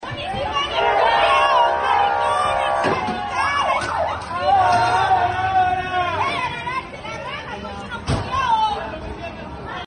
ambiente-ambulantes-vina.mp3